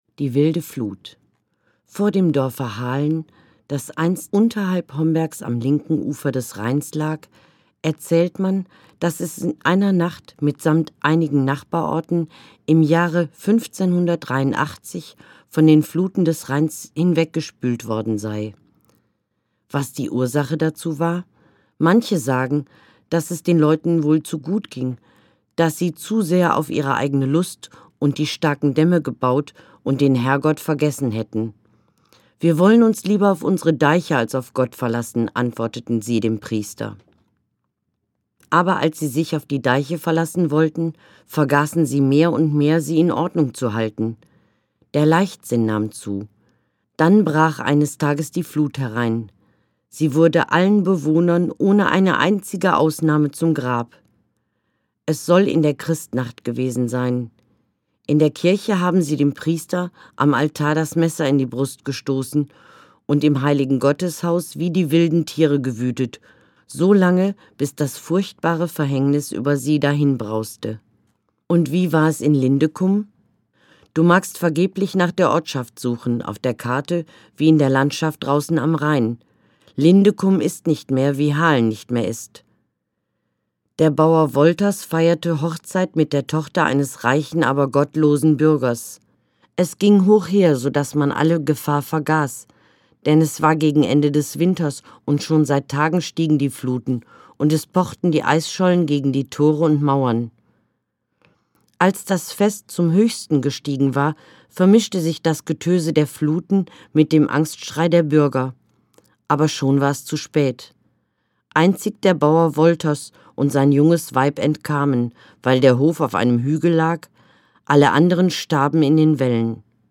Gelesen von